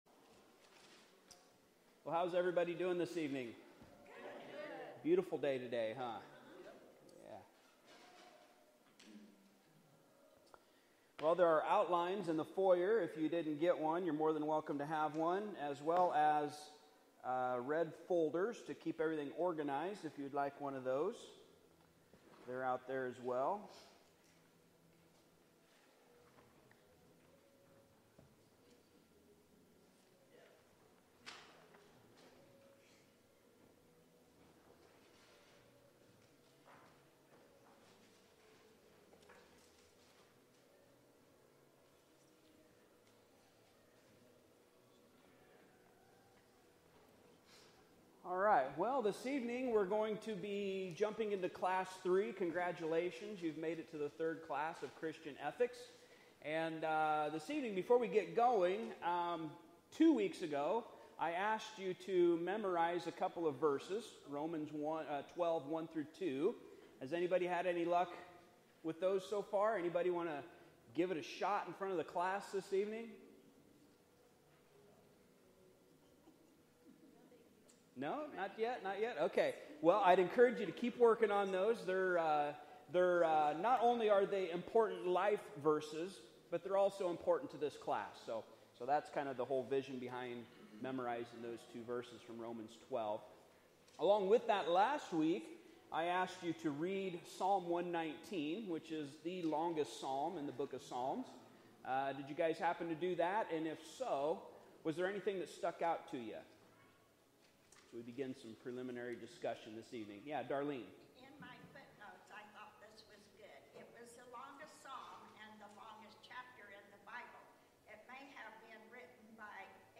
Exodus 20:3 Service Type: Midweek Service Topics